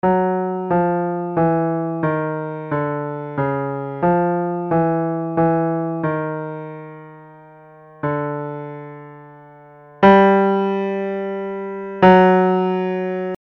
Key written in: D Major
Each recording below is single part only.
a piano